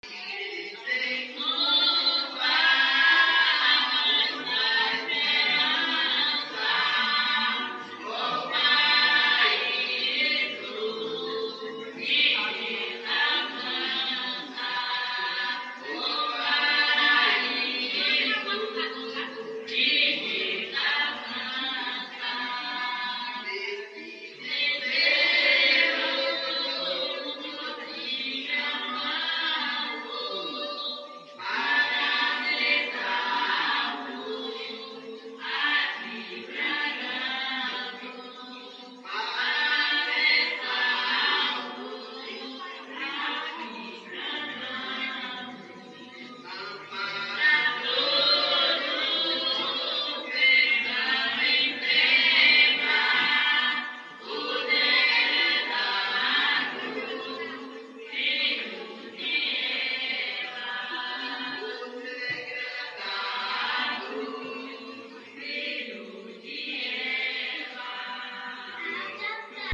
Áudio A reza.mp3